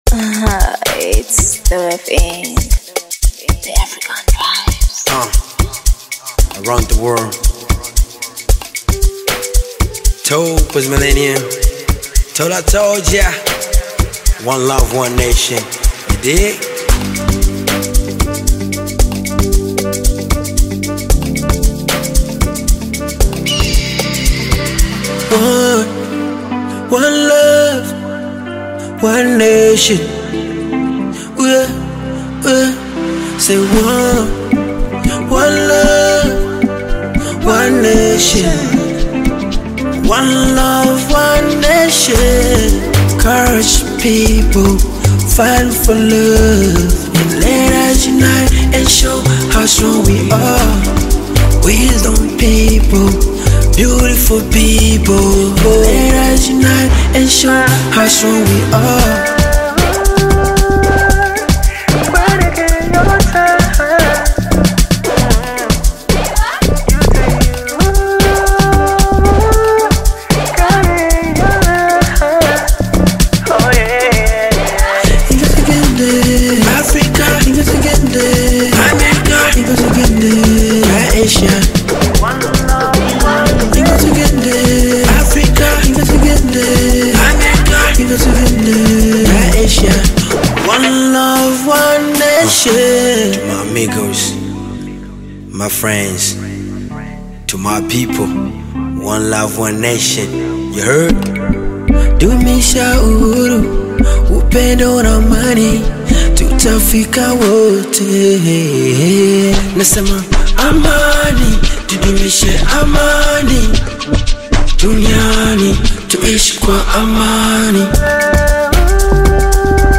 a vibrant, uplifting anthem